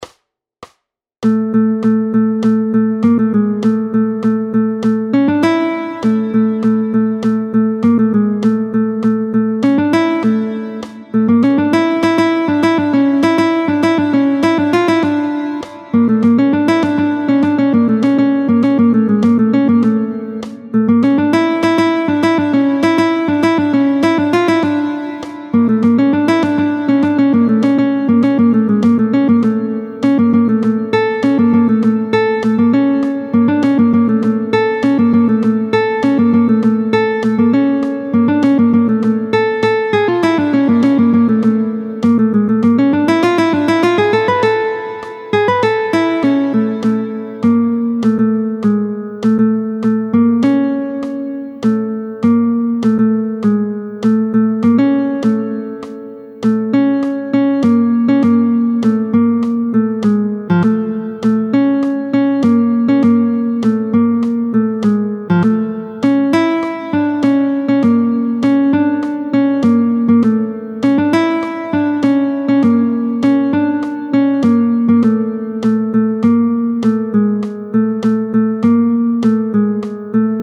√ برای ساز گیتار | سطح پیشرفته